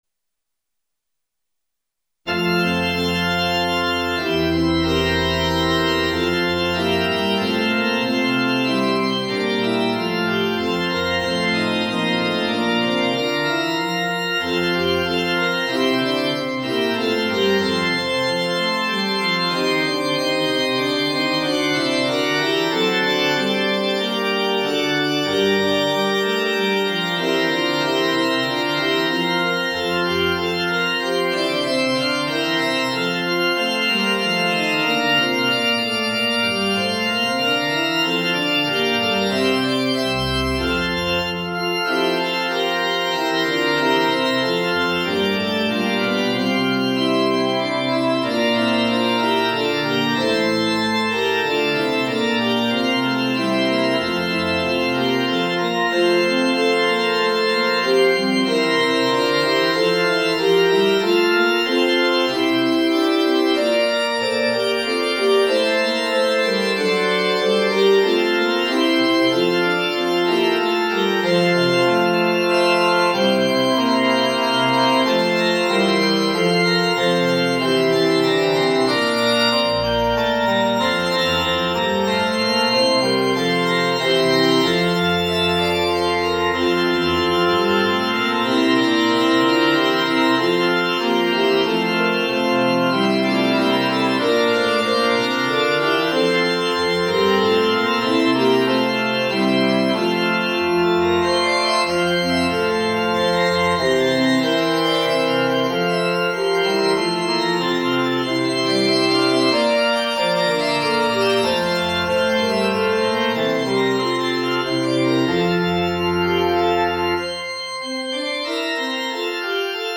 Canon of three voices "Dona Nobis Pacem"　< 平和をわれらに >
- 3声部(一部4声)輪唱 - 器楽伴奏付
後半では間奏を経て今度は逆の順番で各声部が加わり、最後だけ4声部となって終わります。